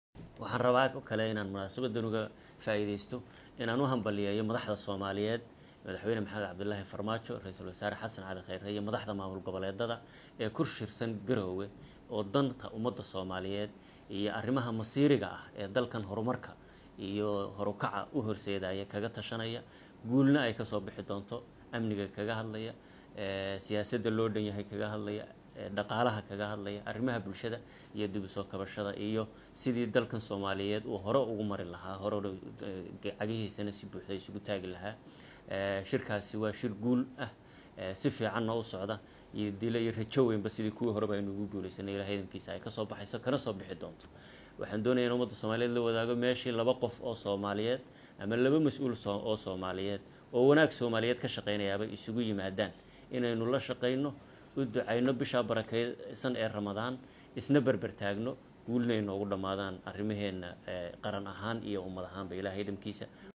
Hoos ka dhageyso ra’iisul wasaare ku xigeenka